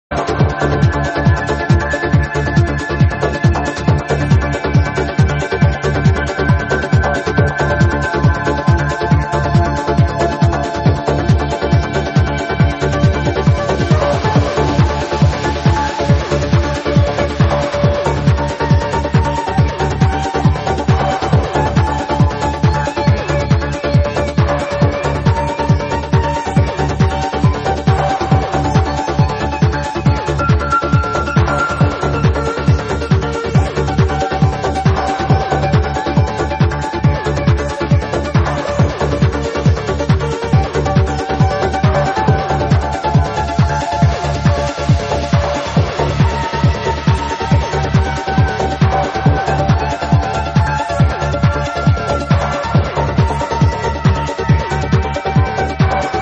this is a nice psy goa tune...
k cool...i want this one especially...sounds pretty good.